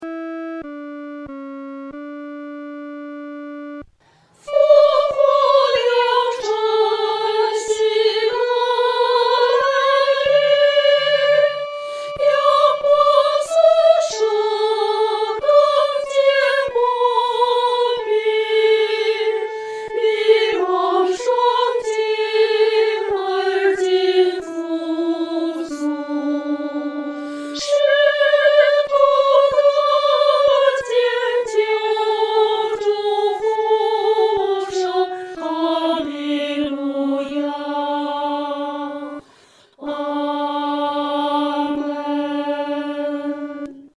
合唱
女高